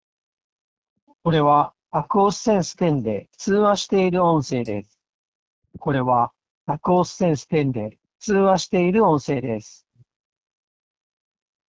超クリアな音声通話
実際にバックグラウンドで喧騒音を大きめに流しながら通話音声を録音したのでチェックしてください。
これがAQUOS sense10で通話している音声。
音声はどちらもクリアで聞き取りやすいですが、周囲の雑音の聞こえ方が全く違いますよね。
AQUOS sense10はほぼ無音。
aquos-sense10-voice.m4a